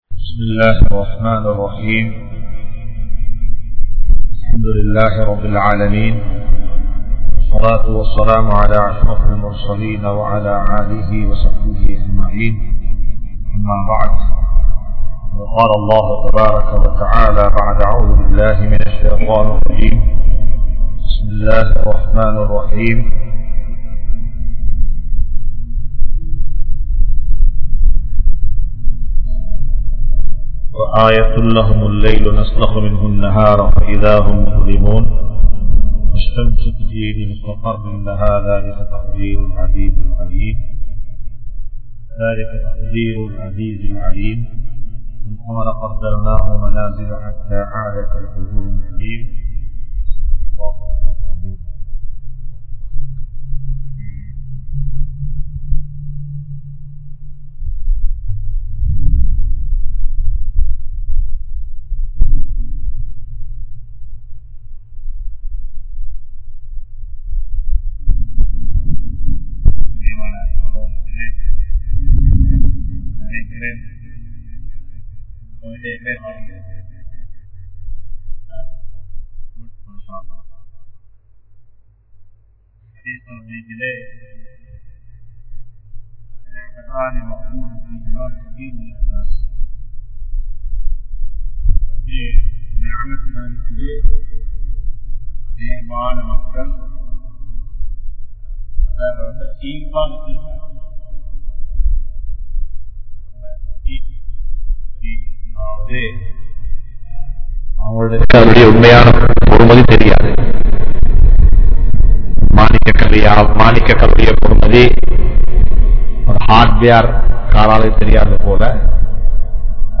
Social Mediavum Indraya Muslimkalum (சமூக வலையத்தலங்களும் இன்றைய முஸ்லிம்களும்) | Audio Bayans | All Ceylon Muslim Youth Community | Addalaichenai
Majma Ul Khairah Jumua Masjith (Nimal Road)